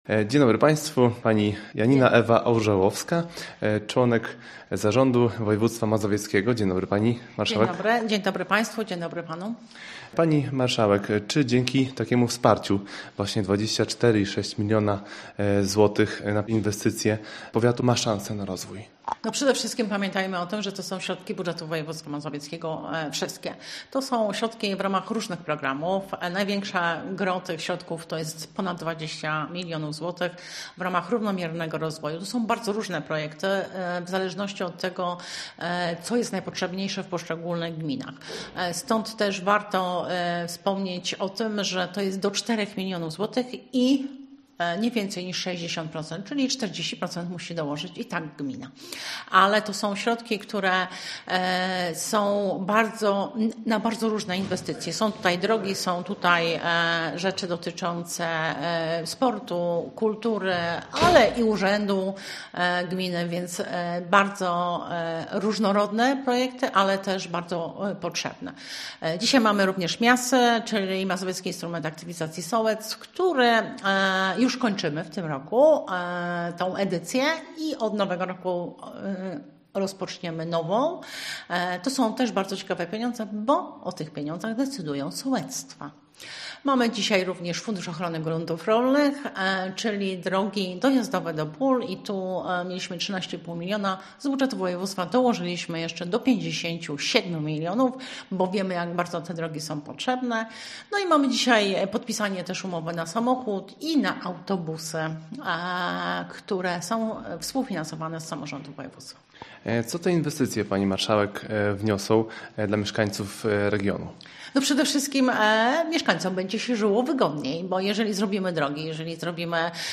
Całą rozmowę z członkinią zarządu województwa mazowieckiego, Janiną Ewą Orzełowską można odsłuchać poniżej:
Spotkanie odbyło się w Urzędzie Gminy Rzewnie.